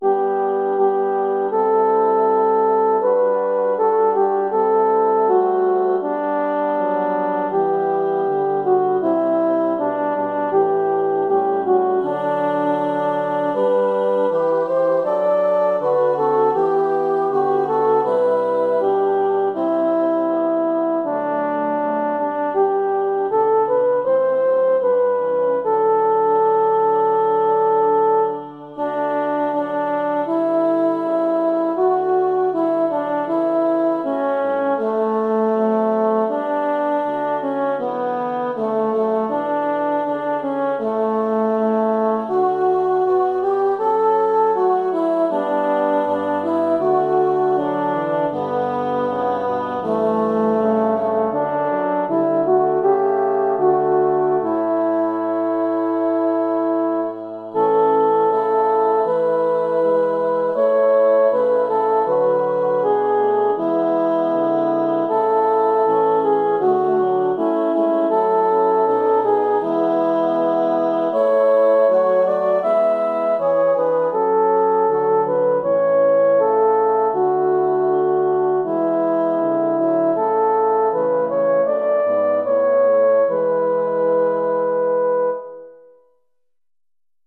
Jordens Gud sop
jordens_gud_sop.mp3